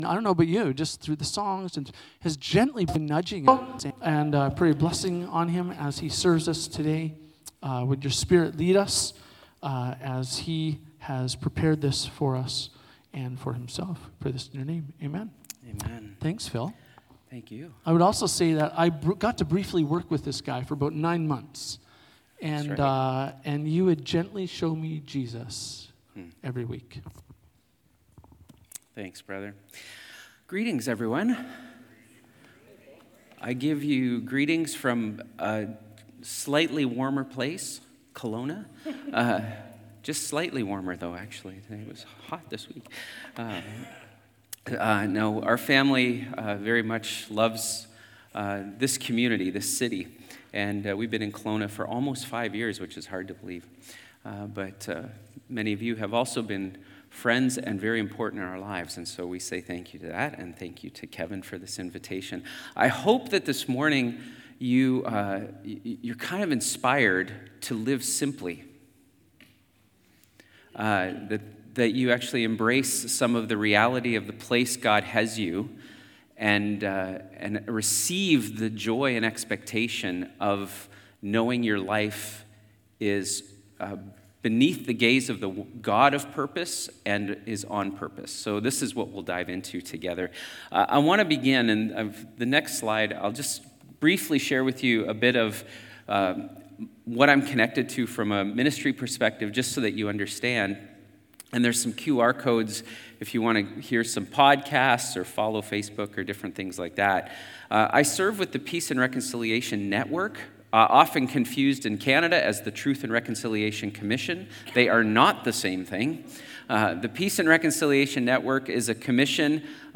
Sermons | Coast Hills Community Church
We invite you to listen along as we pray, worship and learn together.